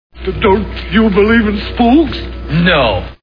The Wizard of Oz Movie Sound Bites